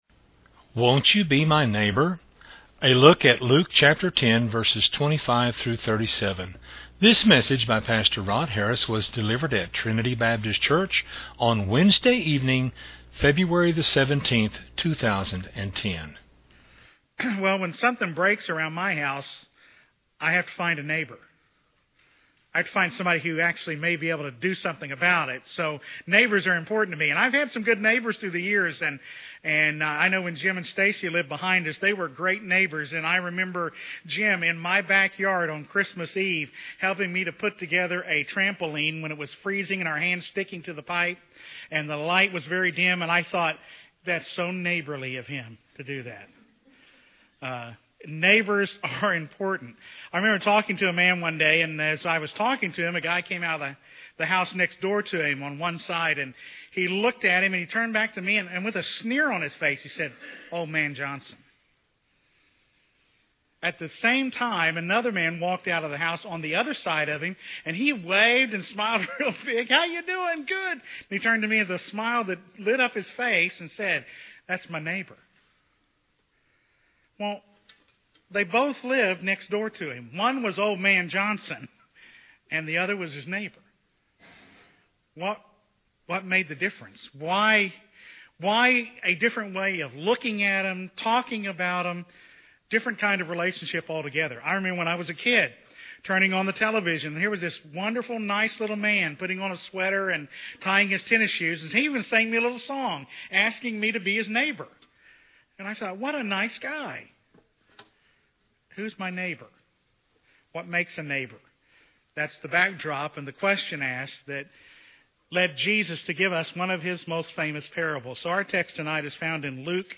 A Bible study around Luke 10:25-37.